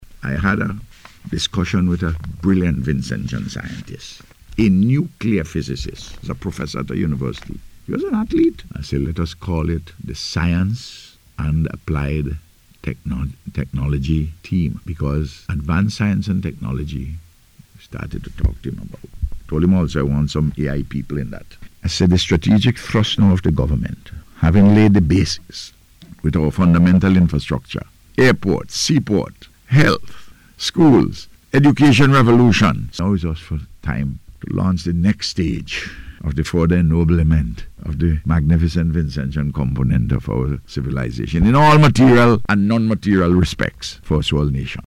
Speaking on radio recently, the Prime Minister revealed that discussions are underway to establish a Science and Applied Technology Team to help drive innovation and growth.